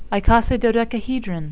(i-co-si-do-dec-a-he-dron)